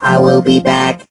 rick_die_05.ogg